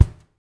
LG KICK2  -R.wav